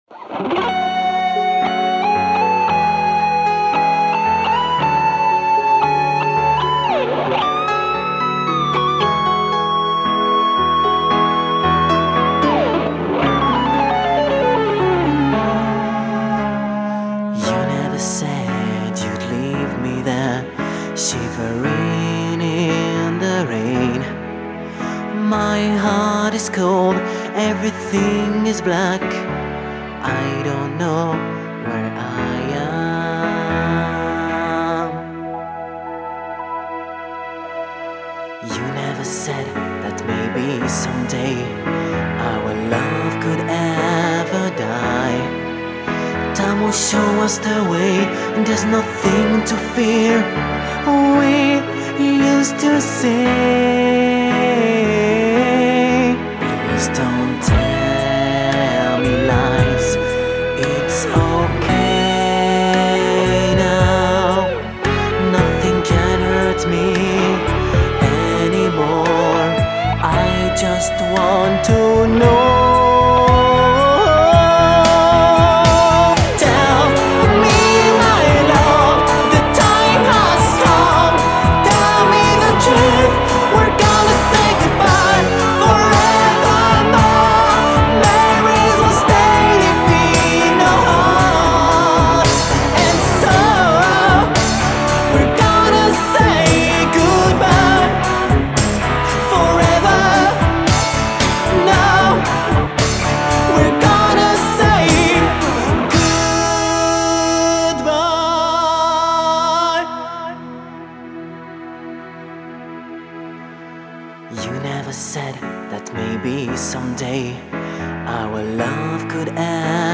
ballad
The guitars in this song are excellent